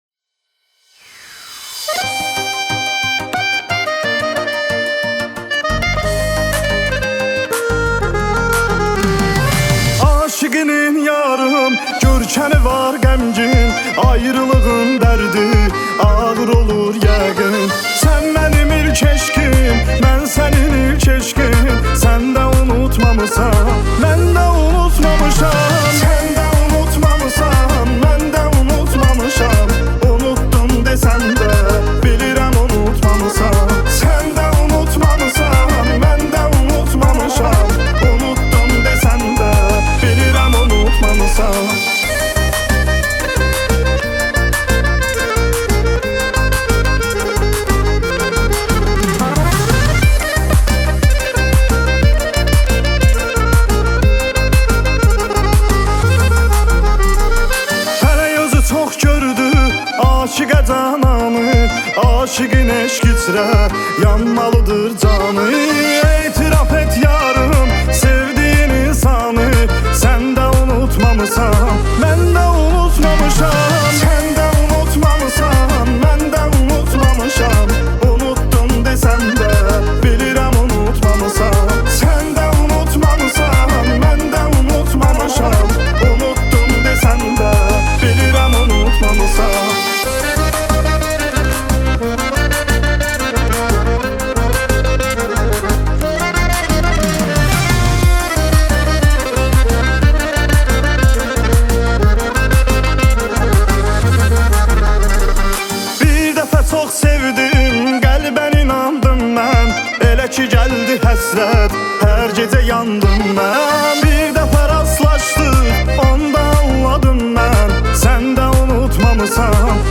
Azeri Muzik